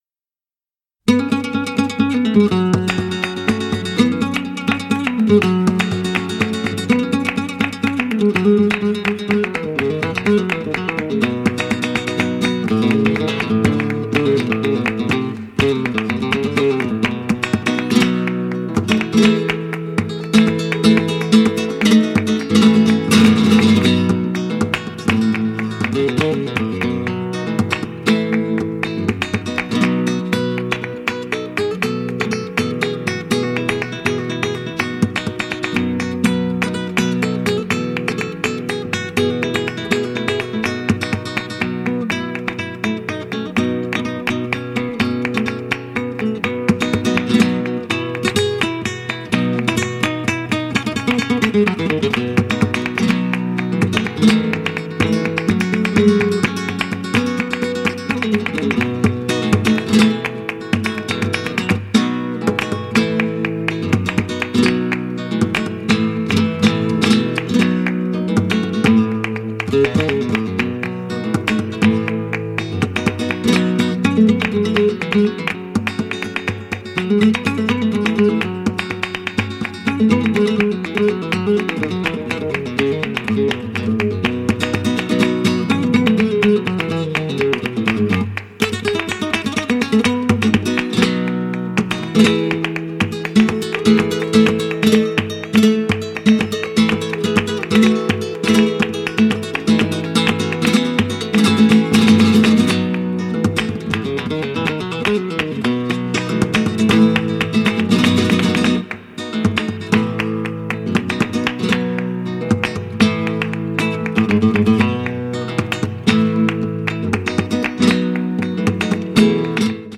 静謐な空気感の中で、情熱的に躍動する名作ギター作品！